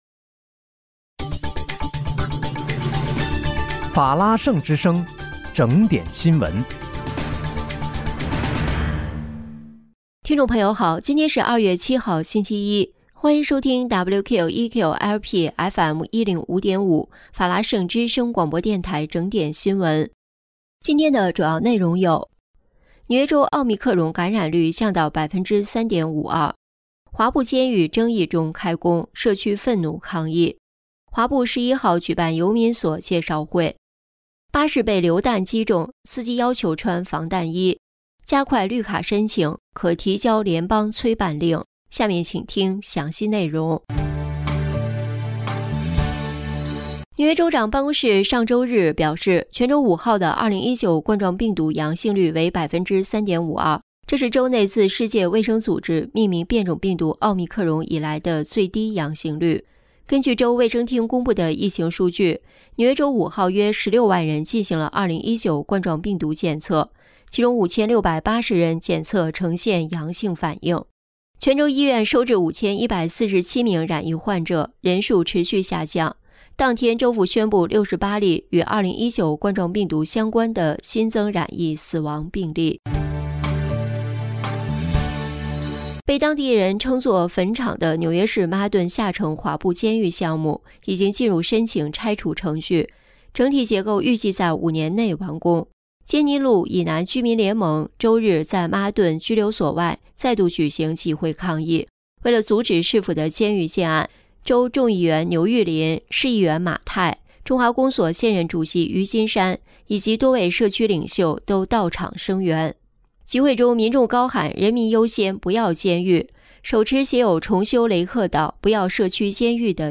2月7日（星期一）纽约整点新闻